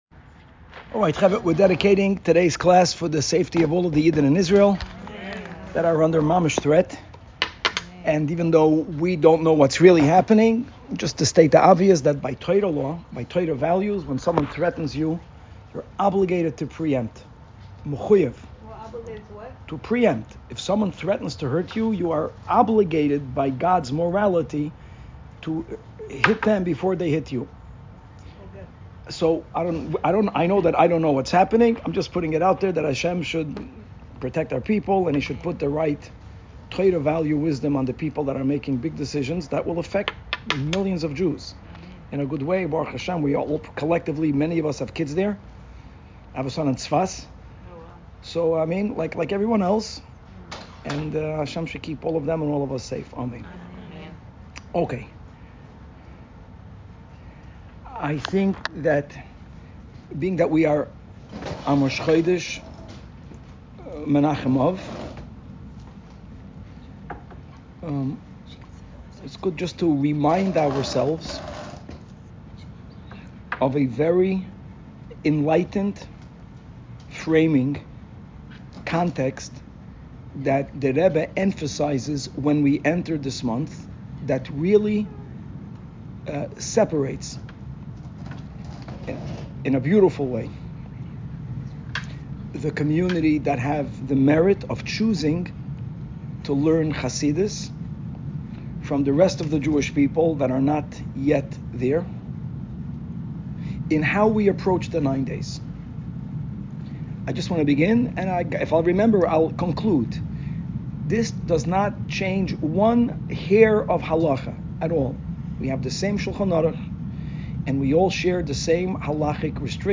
Women's Class